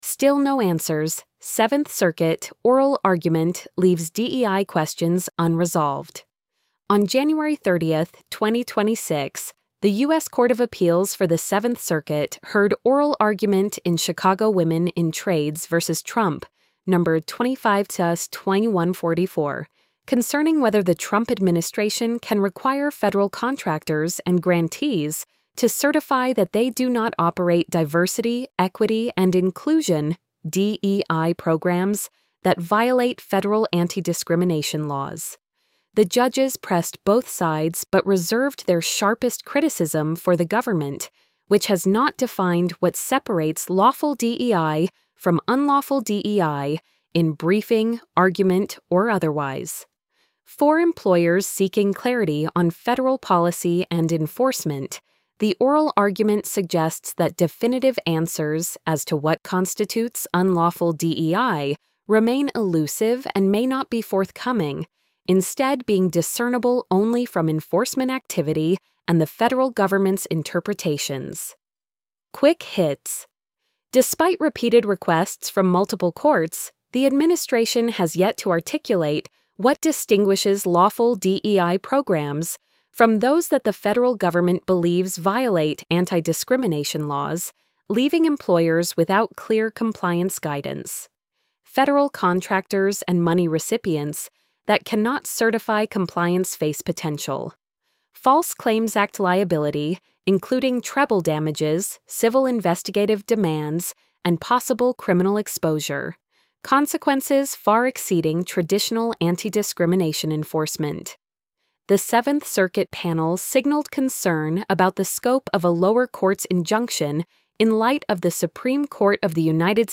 still-no-answers-seventh-circuit-oral-argument-leaves-dei-questions-unresolved-tts-1.mp3